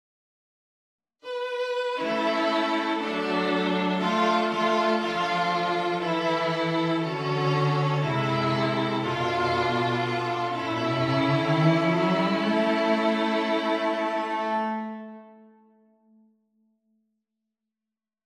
Key written in: A Major
How many parts: 4
Type: SATB
All Parts mix: